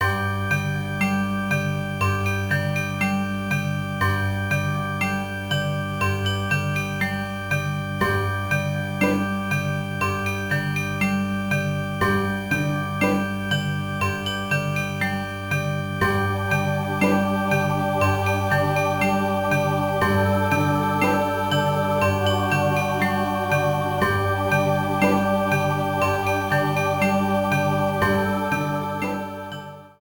Fair use music sample
Clipped to 30 seconds and added fade-out.